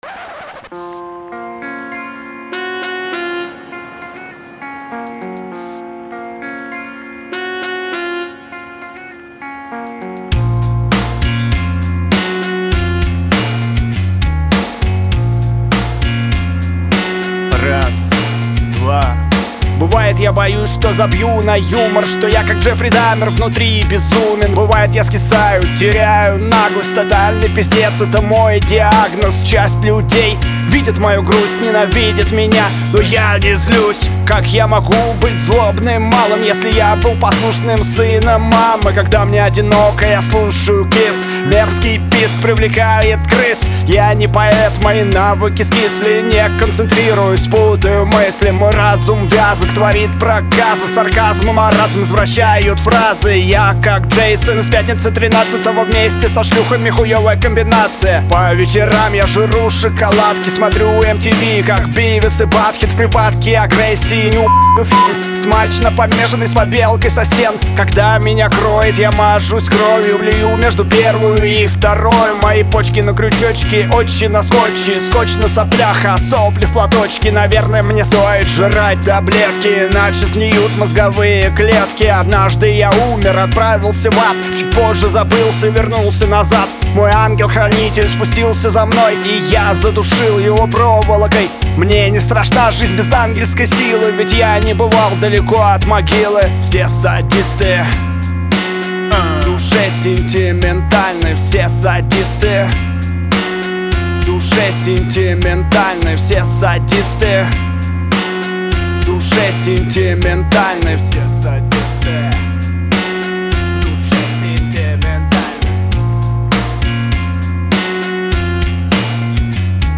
На гитаре сочинил, мне кажется прикольно (и свел тоже)